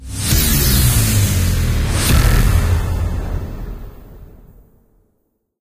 WrongAnswer.ogg